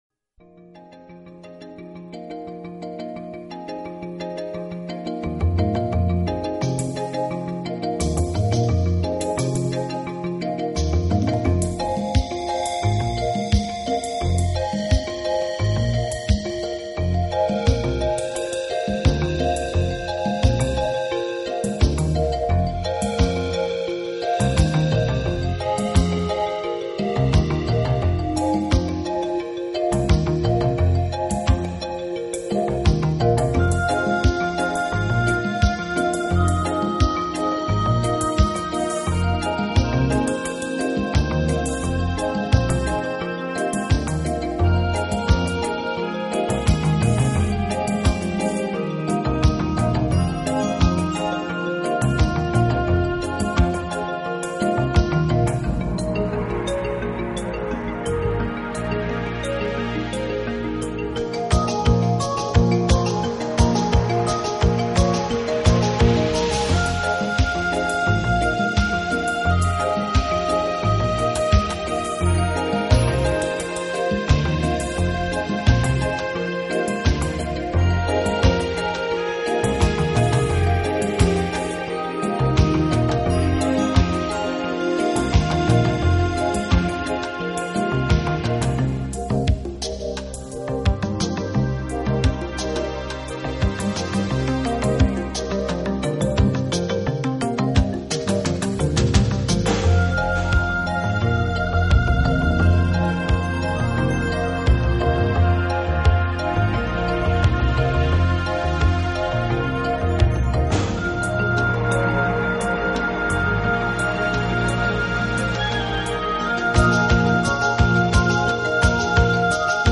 积木”对New Age听众来说将是习以为常的——回旋的合成器声音， 世界